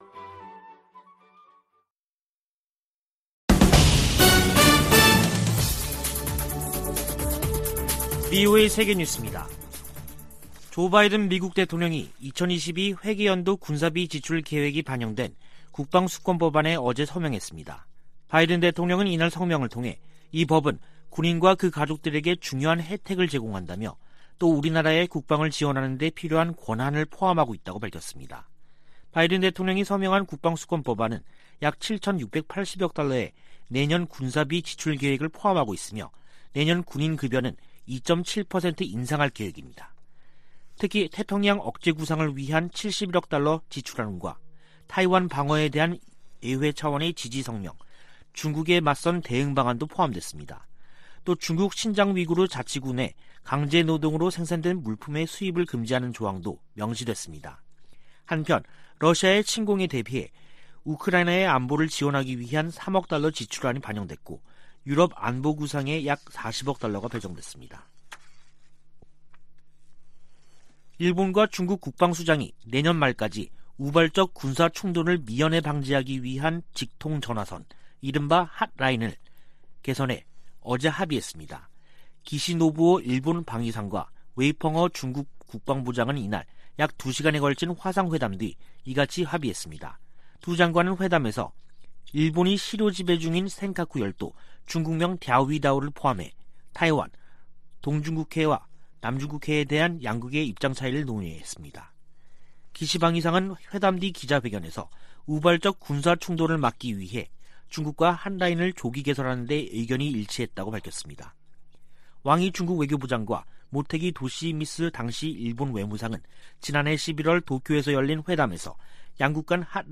VOA 한국어 간판 뉴스 프로그램 '뉴스 투데이', 2021년 12월 28일 2부 방송입니다. 북한이 27일 김정은 국무위원장 주재로 올 들어 네번째 노동당 전원회의를 개최했습니다. 조 바이든 미국 행정부는 출범 첫 해 외교를 강조하며 북한에 여러 차례 손을 내밀었지만 성과를 거두진 못했습니다. 북한을 상대로 소송을 제기한 케네스 배 씨 측이 북한이 소장에 응답하지 않았다며 궐석판결 절차를 요구했습니다.